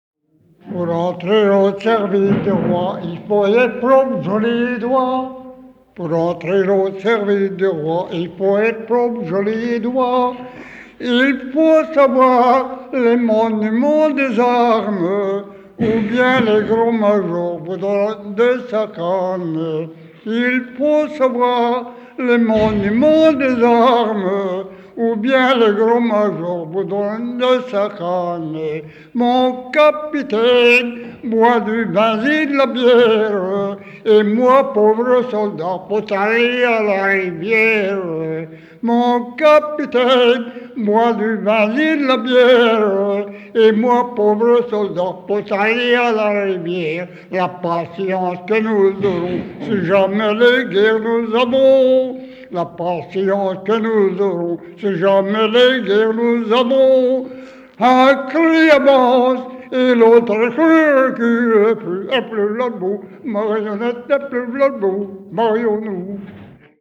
Découvrez notre collection d'enregistrements de musique traditionnelle de Wallonie
Type : chanson d'enfants Aire culturelle d'origine : Ardenne centrale Interprète(s) : Anonyme (homme) Date d'enregistrement : 1953